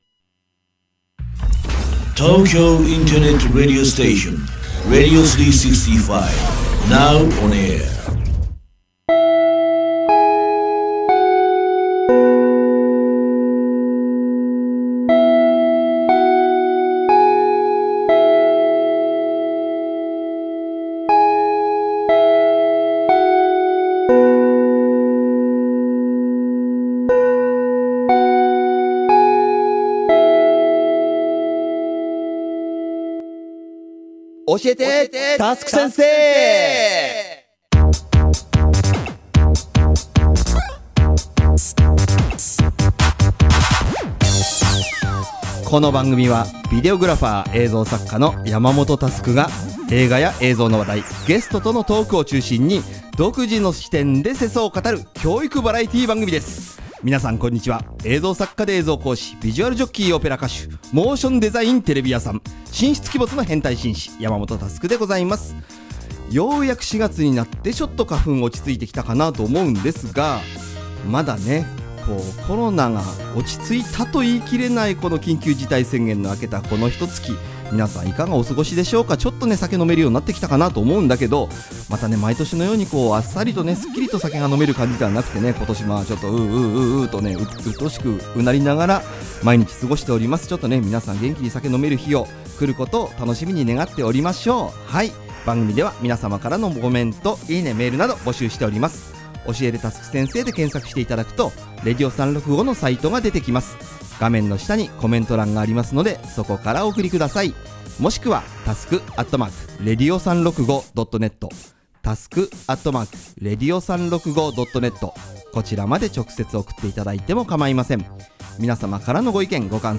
コーナー1：クリエイターズトーク